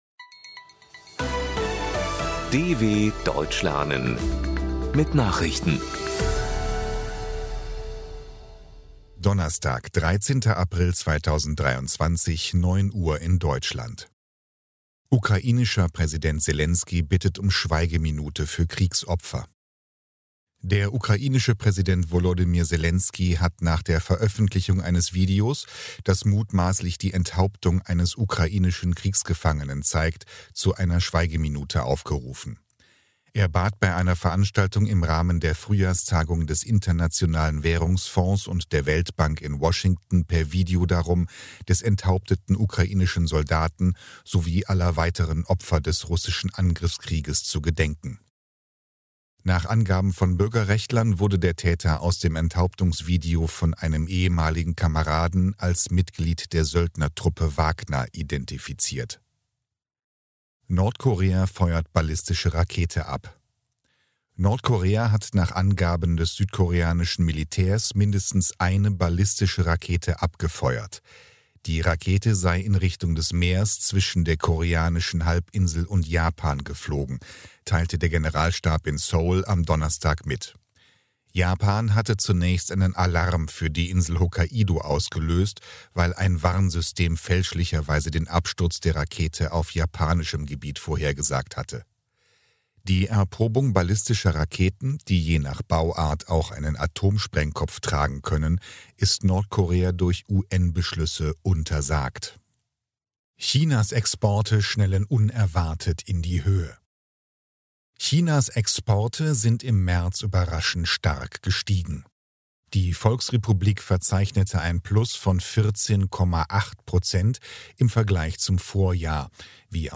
13.04.2023 – Langsam Gesprochene Nachrichten
Trainiere dein Hörverstehen mit den Nachrichten der Deutschen Welle von Donnerstag – als Text und als verständlich gesprochene Audio-Datei.